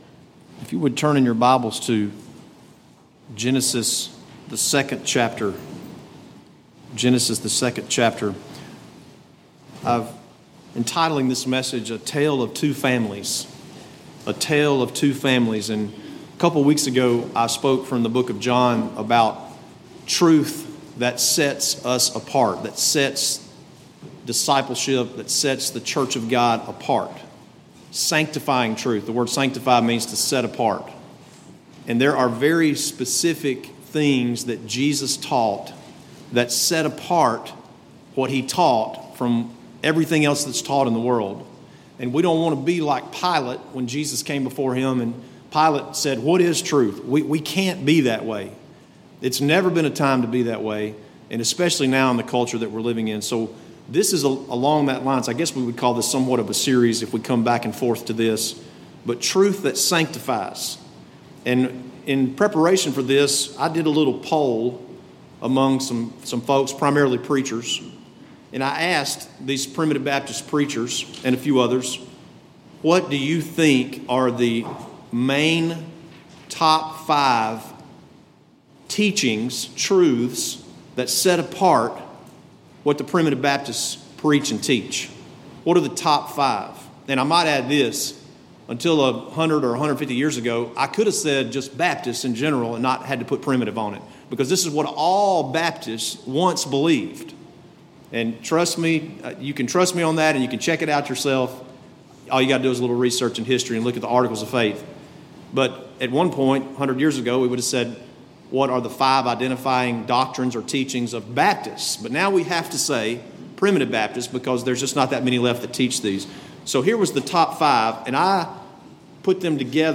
Bethlehem Primitive Baptist Church